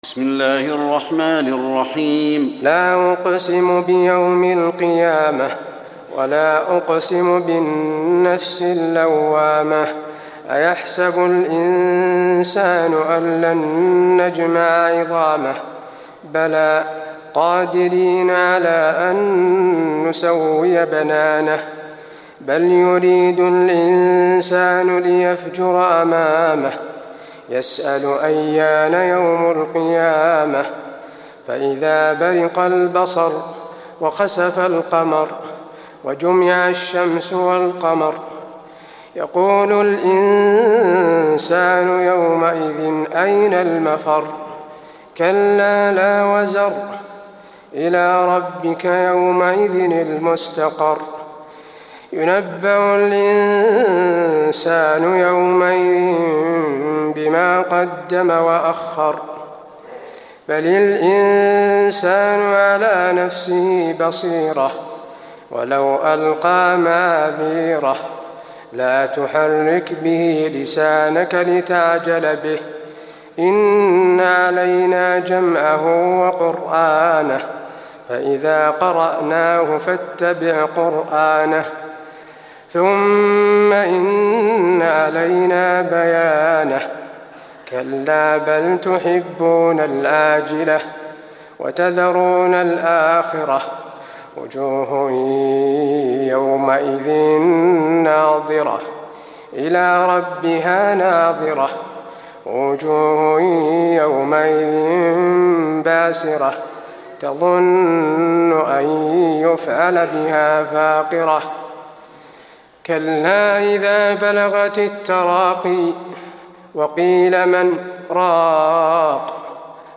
تراويح ليلة 28 رمضان 1423هـ من سورة القيامة الى المرسلات Taraweeh 28 st night Ramadan 1423H from Surah Al-Qiyaama to Al-Mursalaat > تراويح الحرم النبوي عام 1423 🕌 > التراويح - تلاوات الحرمين